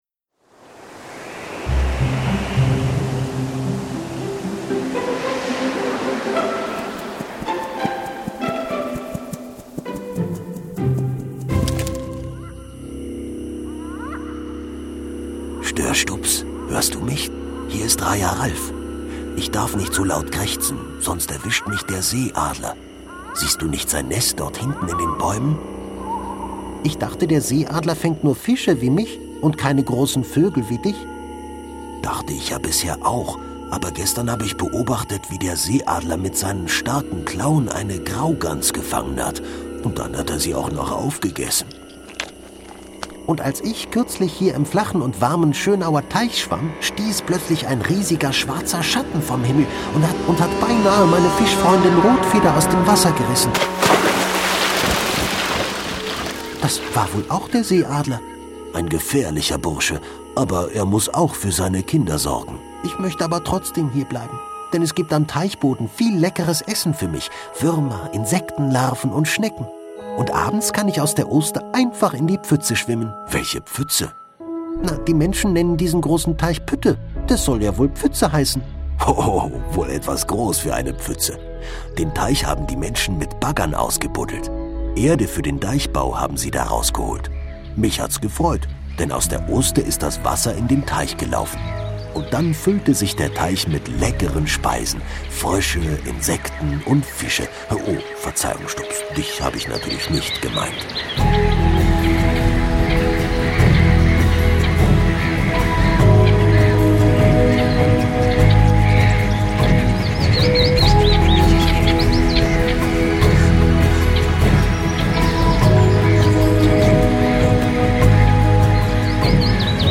Pütte Schönau - Kinder-Audio-Guide Oste-Natur-Navi